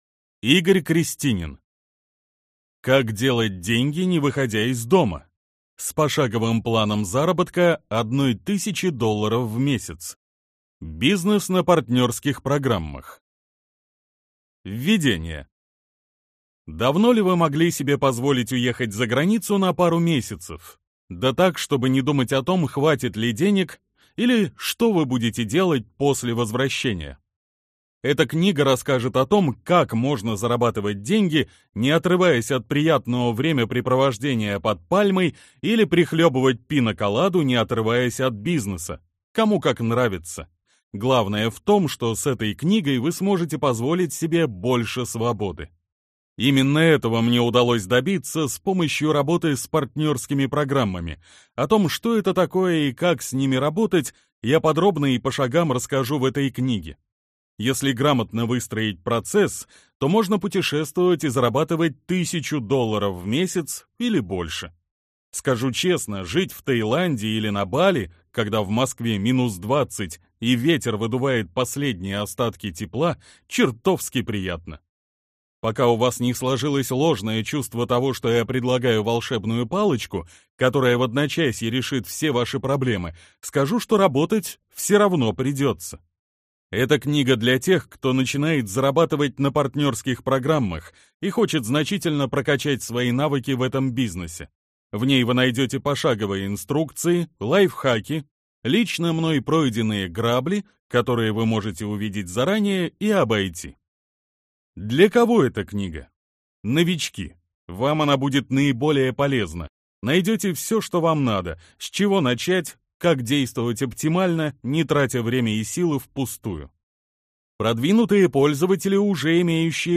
Аудиокнига Как делать деньги, не выходя из дома.